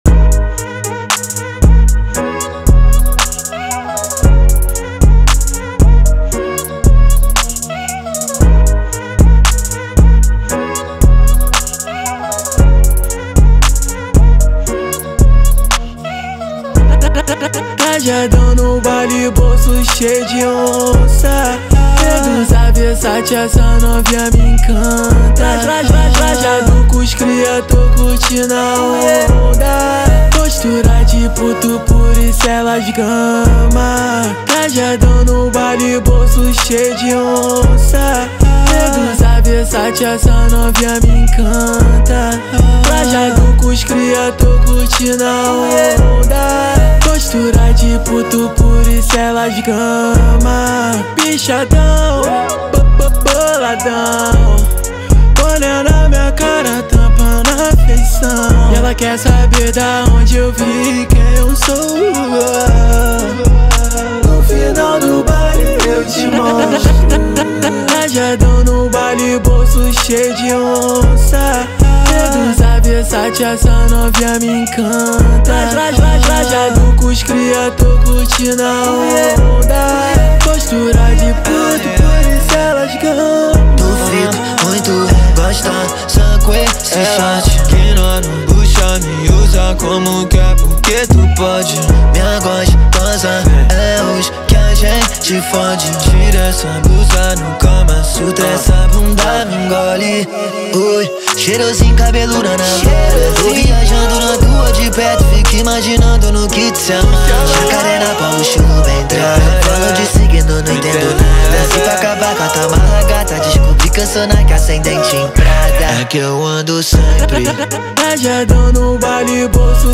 2024-03-03 10:08:48 Gênero: Trap Views